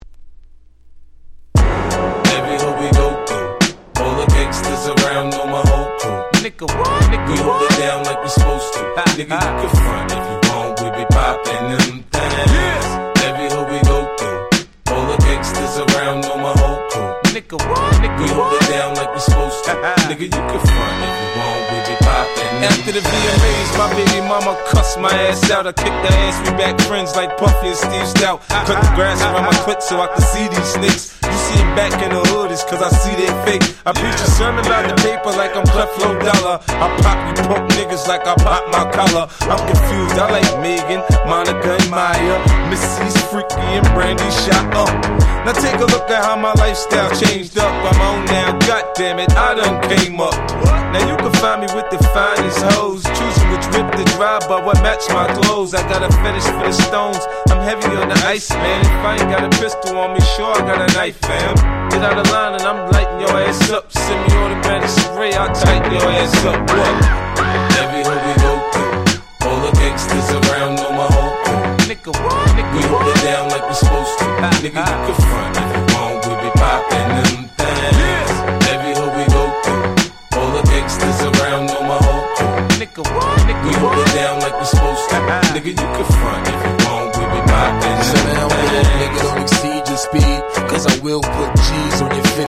03' Smash Hit Hip Hop !!